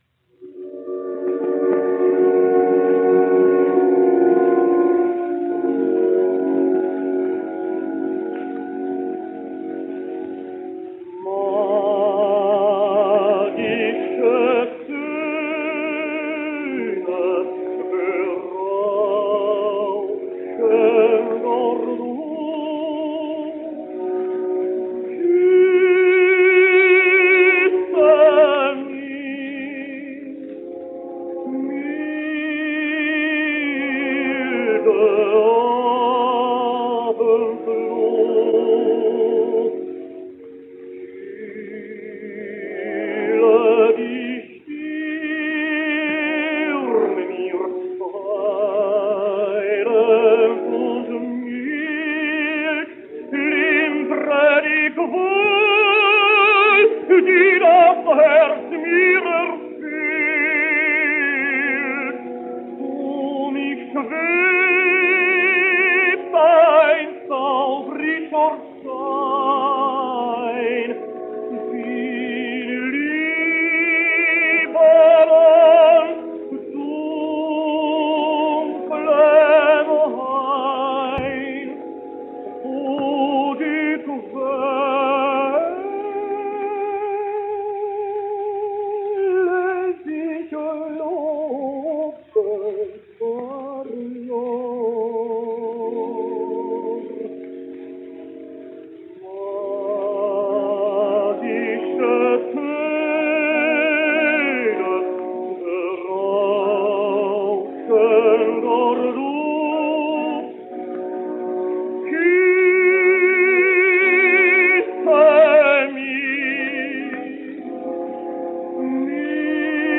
But the first really important German tenor, to leave us with a large output of gramophone records, (375 published titles) was the Moravian tenor Leo Slezak, and he was never merely a vignarian specialist.